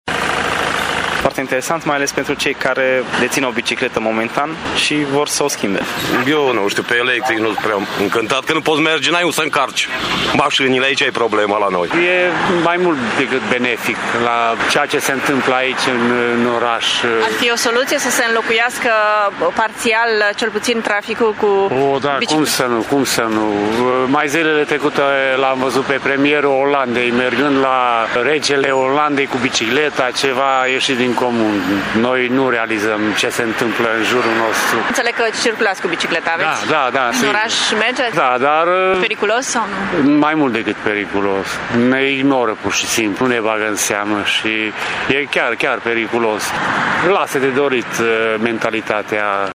Târgumureșenii se arată foarte interesați, în special de programul pentru biciclete, însă mai întâi trebuie să se schimbe mentalitatea oamenilor: